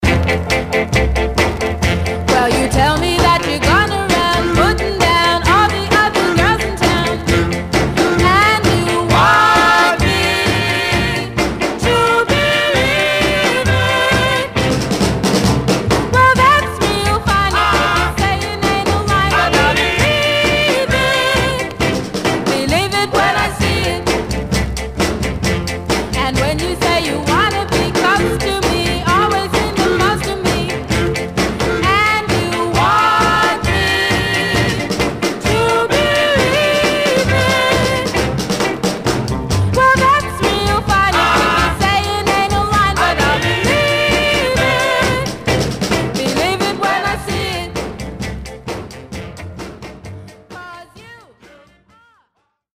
Mono